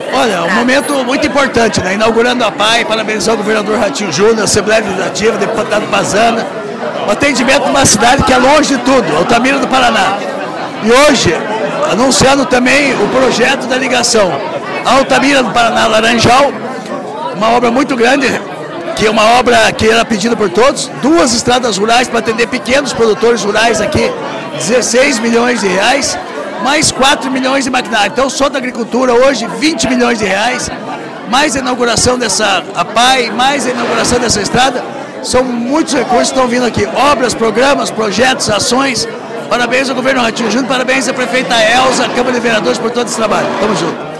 Sonora do secretário da Agricultura e do Abastecimento, Marcio Nunes, sobre a inauguração da Apae em Altamira do Paraná